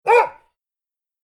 perro.mp3